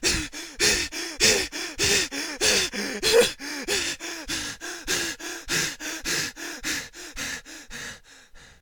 panic_1.ogg